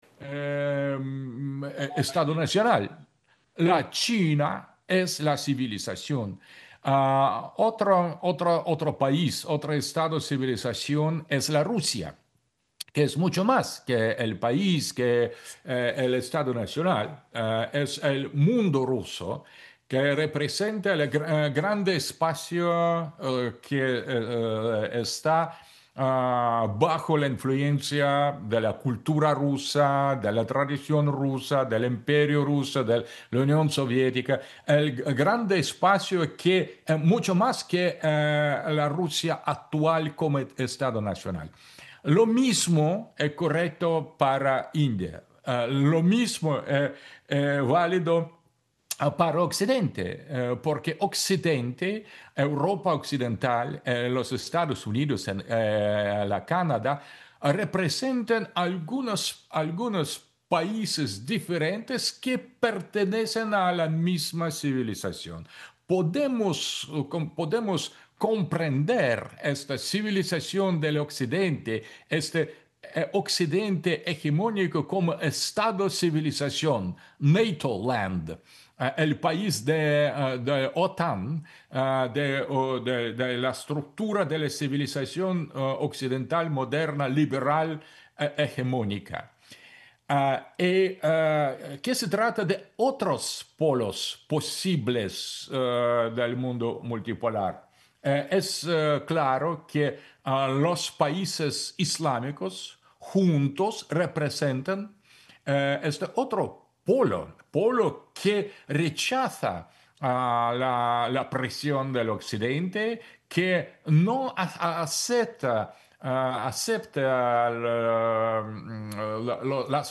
Discurso de Aleksandr Duguin en el Foro Nueva Era Digital, Fake News y Guerra Cognitiva, en Caracas
El filósofo ruso, Aleksandr Duguin, señaló este viernes que naciones como Rusia, China, India y las que conforman el mundo islámico mantienen una lucha en defensa de su identidad y soberanía, lo cual aseguró durante su participación mediante videollamada en el Foro Nueva Era Digital, Fake News y Guerra Cognitiva.